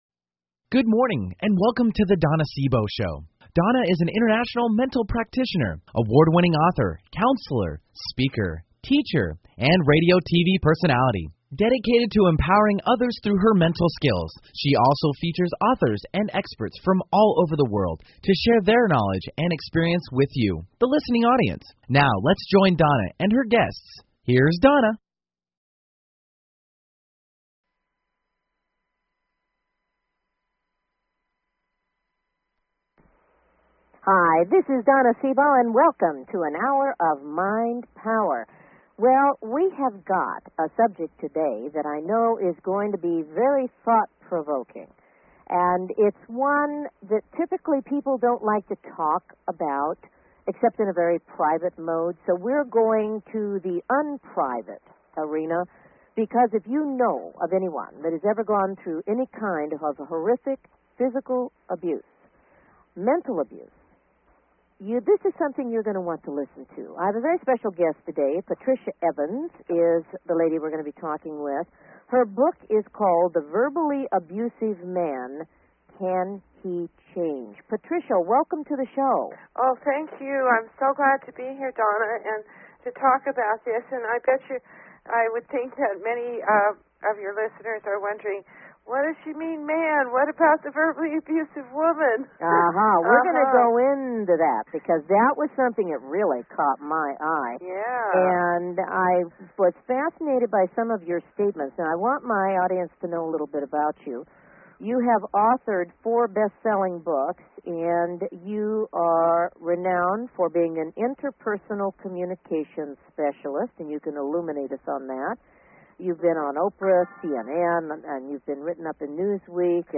If you have someone in your environment who fits this description don't miss this interview.